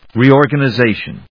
音節re・or・gan・i・za・tion 発音記号・読み方
/rìː`ɔɚgənɪzéɪʃən(米国英語), ˌri:ɔ:rgʌnʌˈzeɪʃʌn(英国英語)/